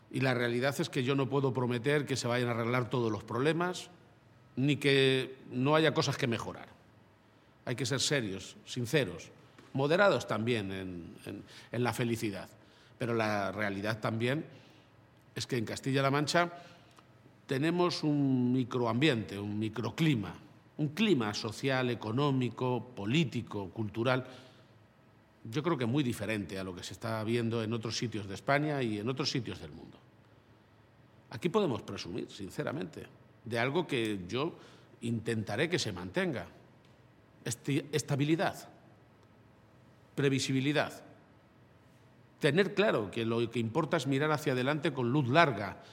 En este mismo sentido, y durante el tradicional mensaje de Año Nuevo, que un año más se ha escenificado en el toledano Palacio de Fuensalida, sede de la Presidencia regional, García-Page ha asegurado que en Castilla-La Mancha “somos una garantía de que el día de mañana, frente a muchas aventuras, cuando haya que hablar de España, de los derechos de las y los ciudadanos de mi tierra, hablaremos todos”.